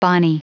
Prononciation du mot bonnie en anglais (fichier audio)
Prononciation du mot : bonnie